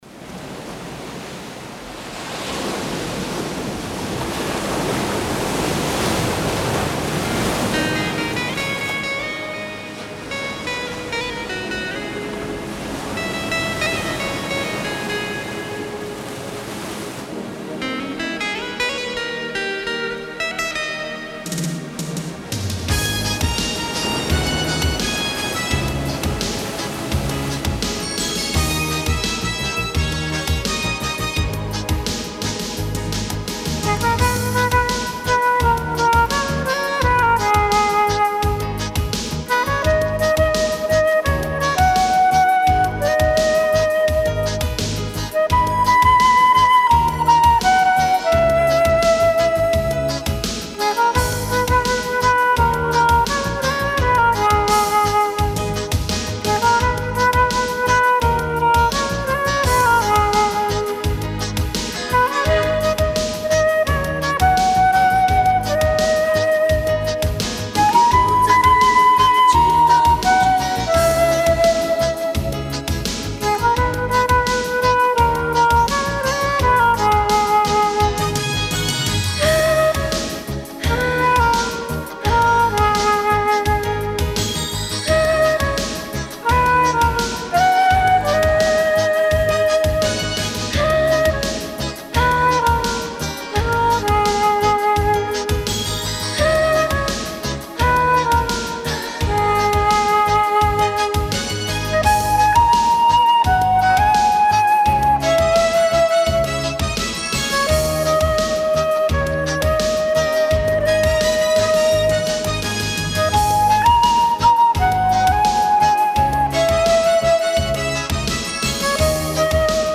休闲音乐
纯净清新的大自然音乐，让您的心情获得轻松宁静。